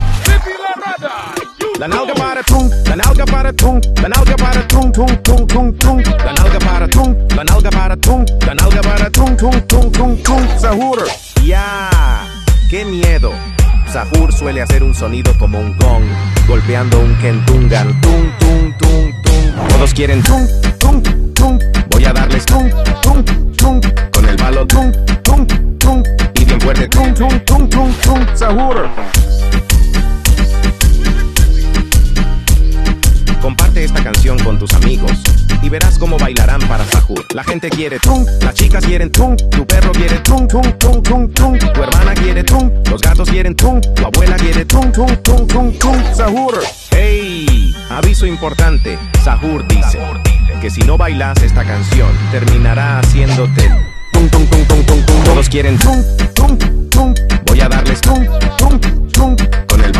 tung tung tung meme sound effect